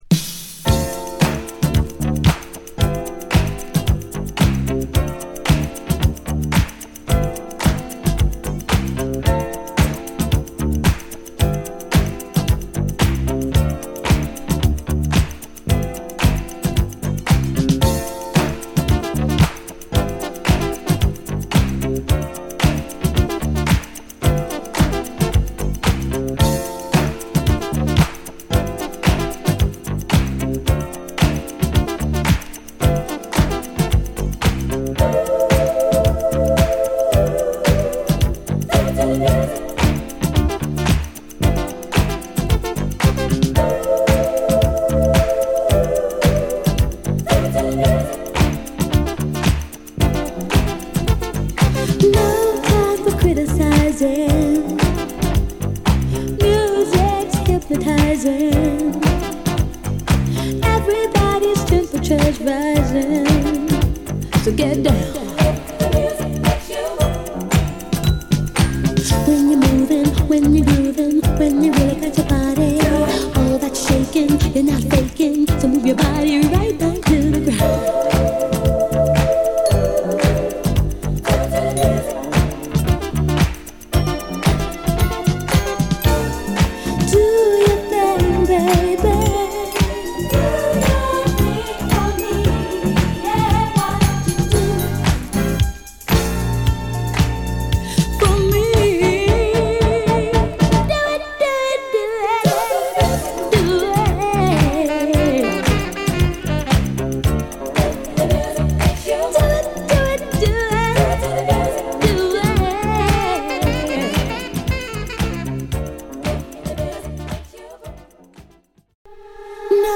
リマスター盤